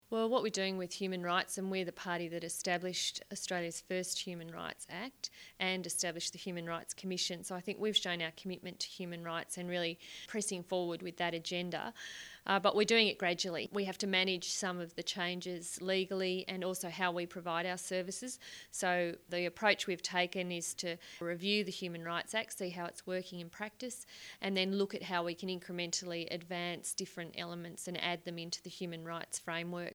Listen to Ms Gallagher explain how the Labor Government is implementing the recommendations from the 2010 Report: